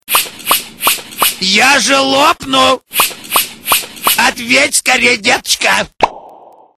Категория: Рингтоны приколы